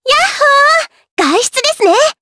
Dosarta-vox-select_jp.wav